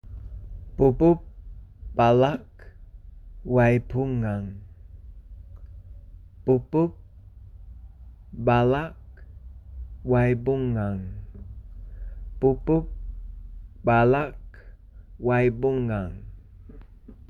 hear proununiciation - opens in new tab(opens in a new window)) initiative is a Victorian Government early childhood initiative.